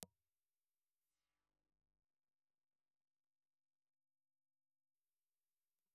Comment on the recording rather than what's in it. Dynamic Cardioid Impulse Response file of the Bell "Gallows" transmitter. Bells_Gallows_IR.aiff